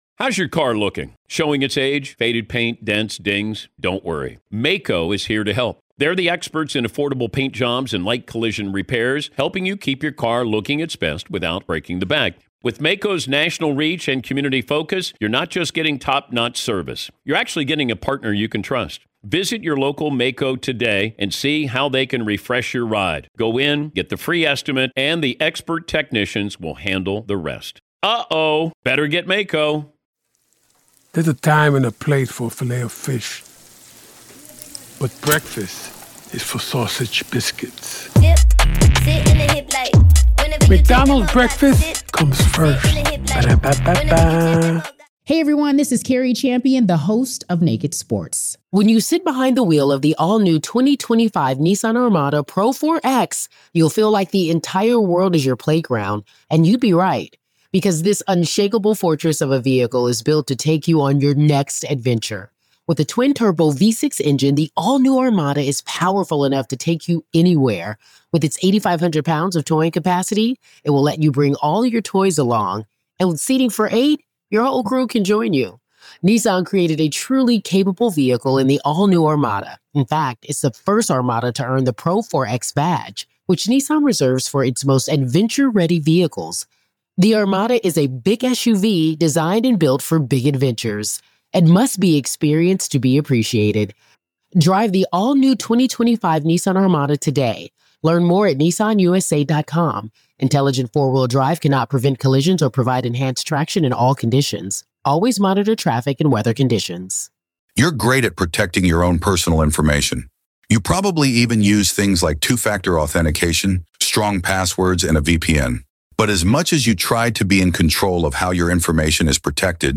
In a candid conversation